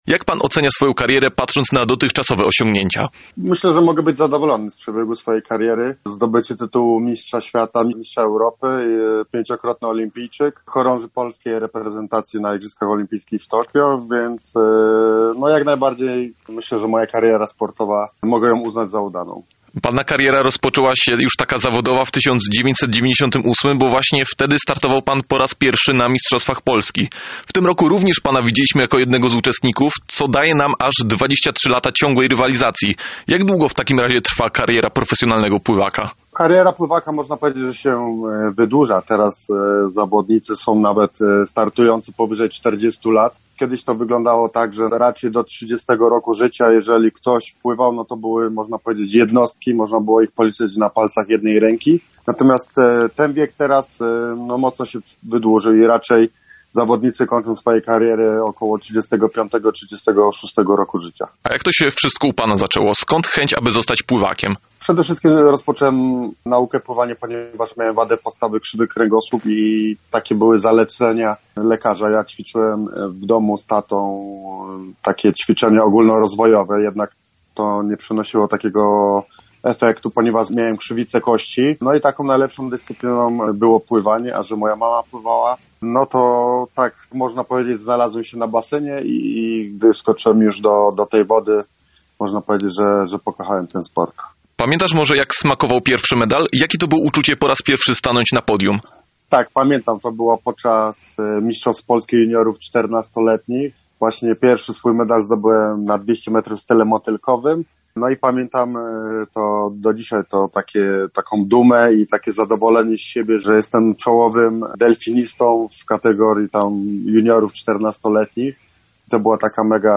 Pawel-Korzeniowski-wywiad-v2.mp3